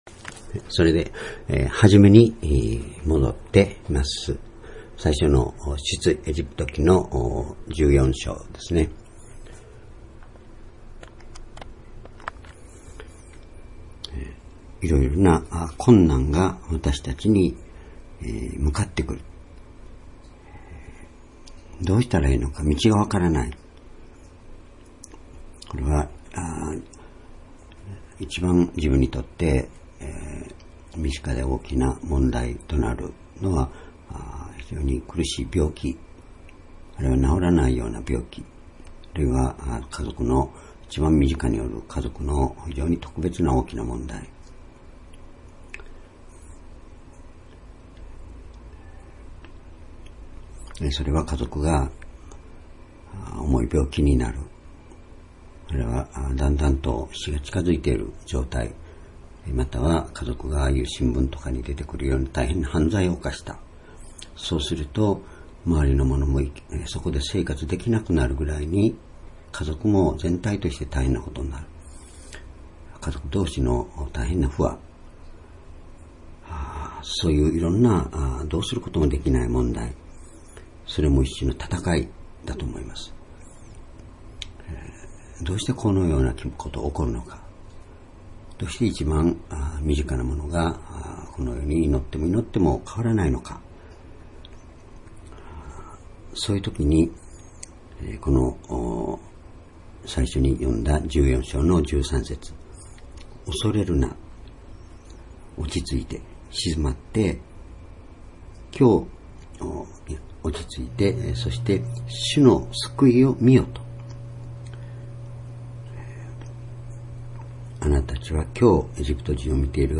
（主日・夕拝）礼拝日時
聖書講話箇所